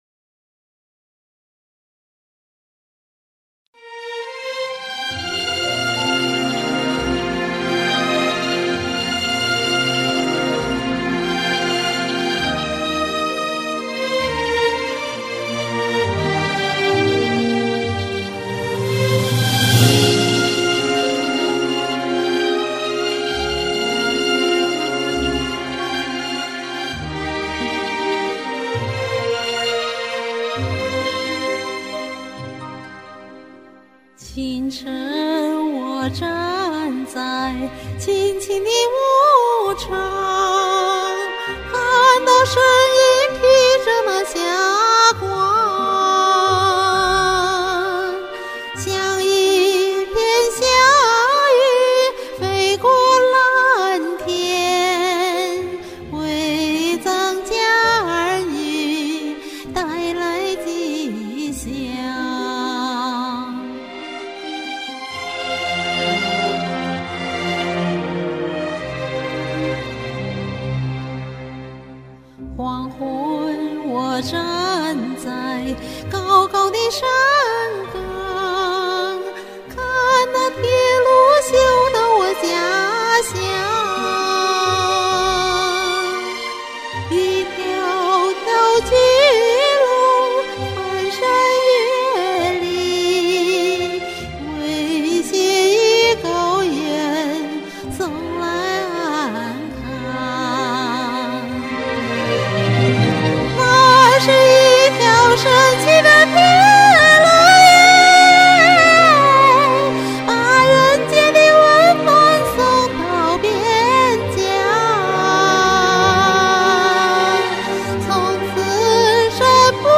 经典民歌
结果一听录音，傻眼了：这歌被我唱成原生态了，两段的副歌还都被我唱得过载了。
大气，舒展，悠扬，精彩！